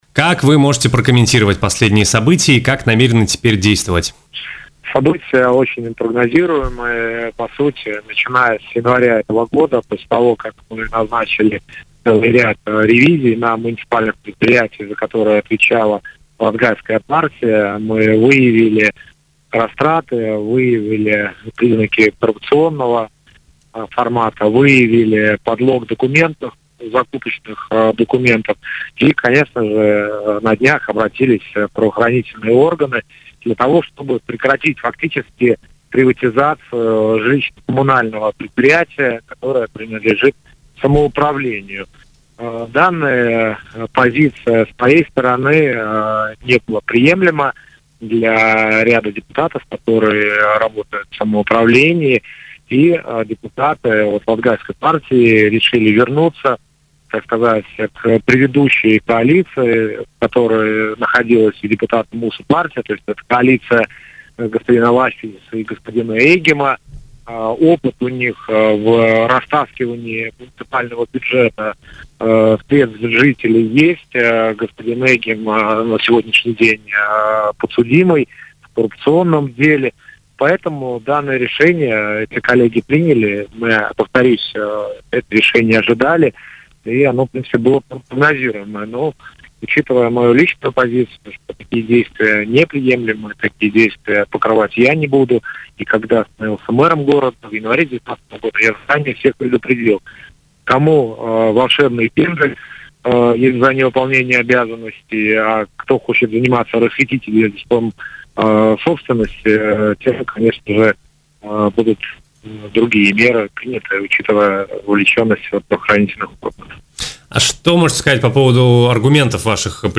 В эфире радио Baltkom Элксниньш рассказал, что ранее он начал в Даугавпилсе ряд ревизий, в результате которых были выявлены коррупционные схемы, что было неприемлемо для ряда депутатов.